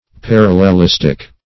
Search Result for " parallelistic" : The Collaborative International Dictionary of English v.0.48: Parallelistic \Par`al*lel*is"tic\, a. Of the nature of a parallelism; involving parallelism.
parallelistic.mp3